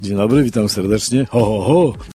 Samorządowcy jako pierwsi pojawili się w Mikołajkowym Autobusie Radia 5 w Ełku.
A tak przywitał się starosta powiatu ełckiego Marek Chojnowski.